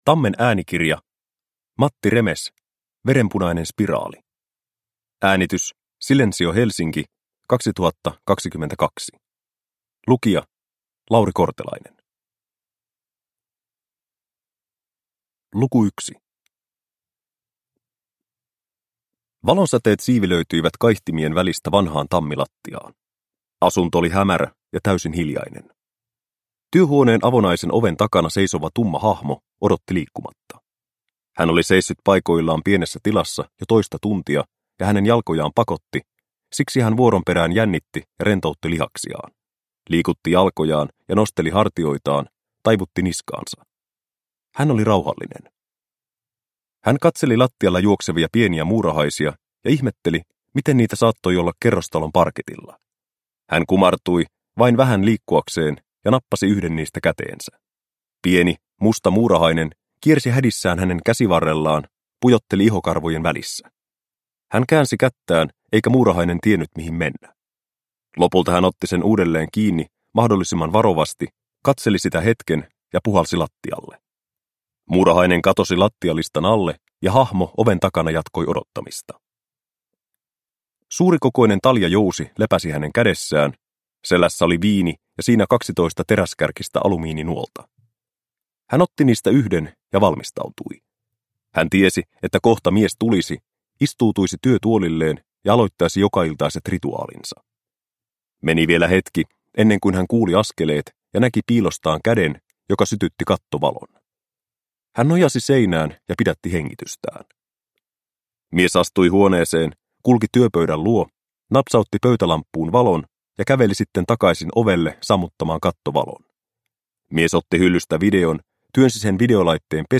Verenpunainen spiraali – Ljudbok – Laddas ner